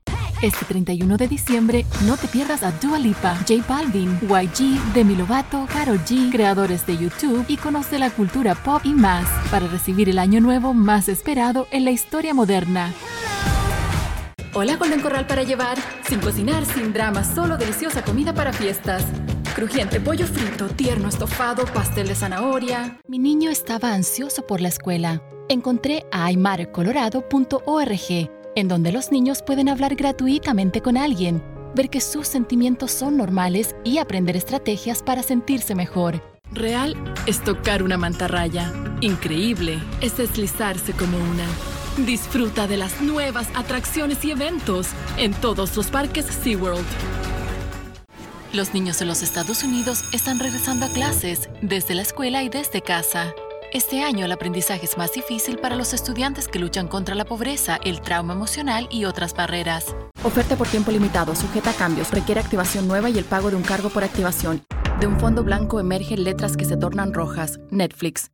Commercial Spanish.mp3